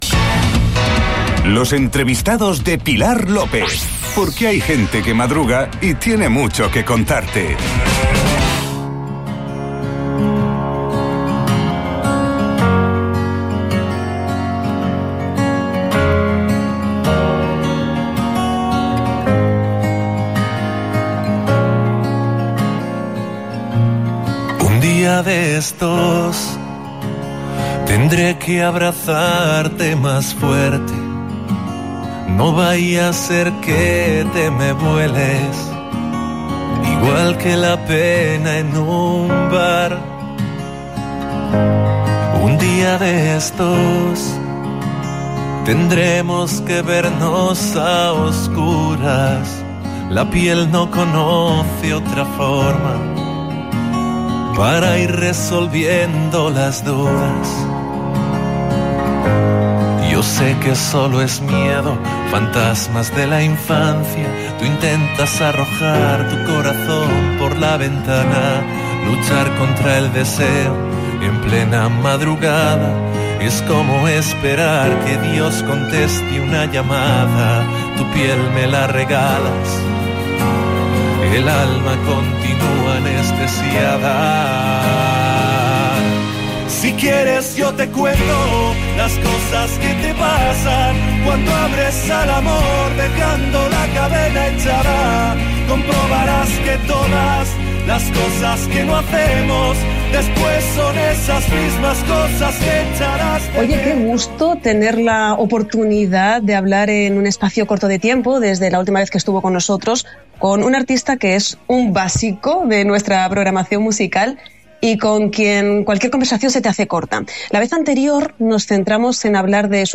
ENTREVISTA-AL-CANTAUTOR-MARWAN-ANTES-DE-SUS-2-CONCIERTOS-EN-LAS-ISLAS.mp3